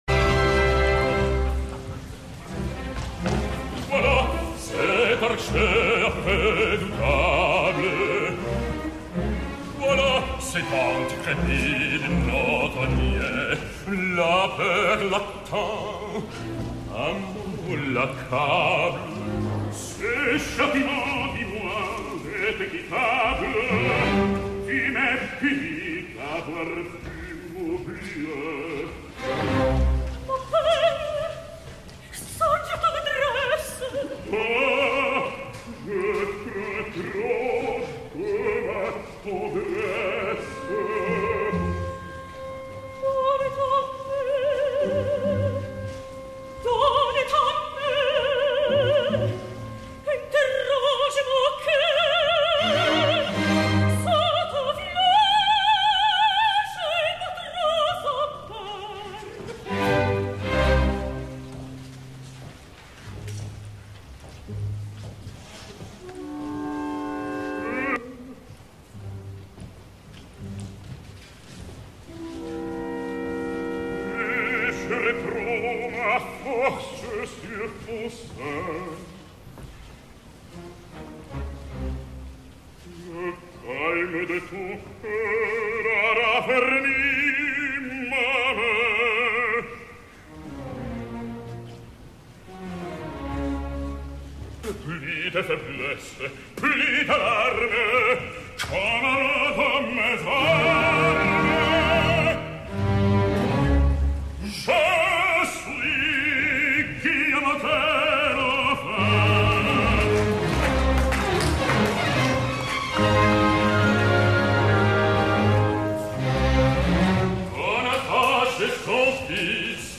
Guillaume Tell, congiurato svizzero – Carlos Alvarez
Orchestra e Coro del Teatro Comunale di Bologna
Director musical: Michele Mariotti
Teatro Comunale de Bologna 8 d’octubre de 2014